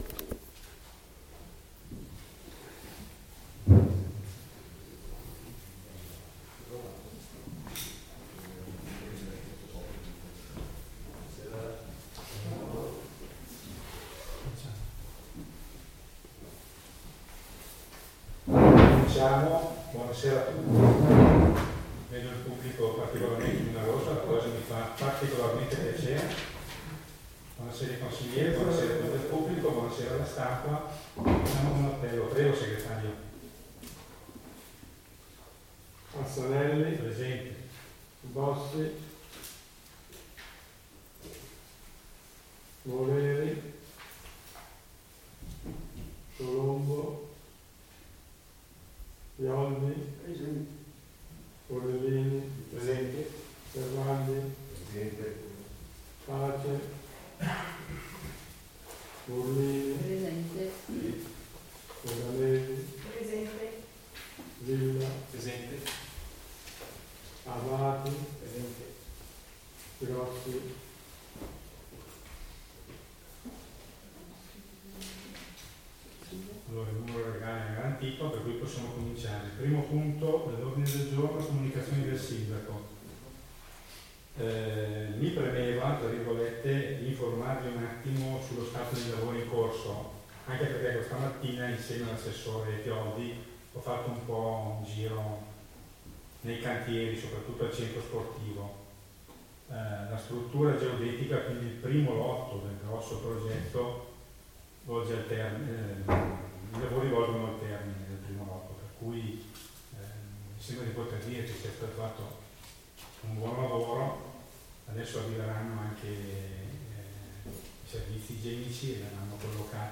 Registrazione seduta consiliare del 07.03.2023 | Comune di Fara Gera d'Adda